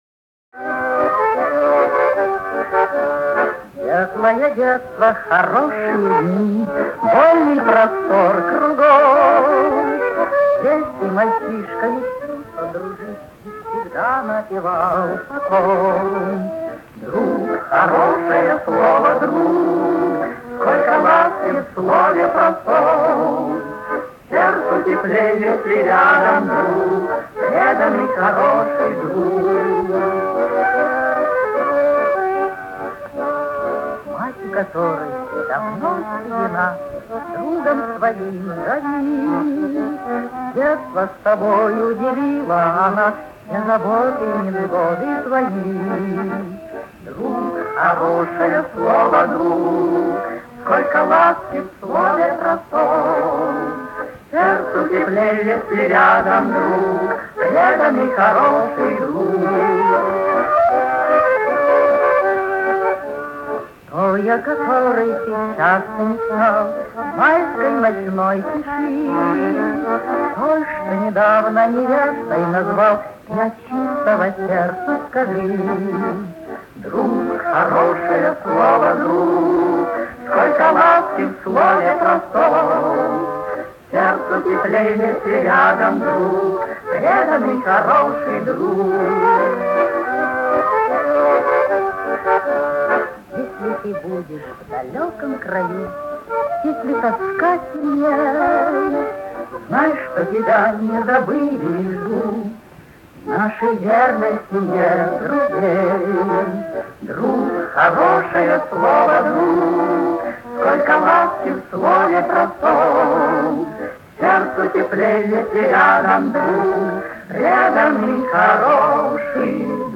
Еще одна песня из архивов, простая и задушевная.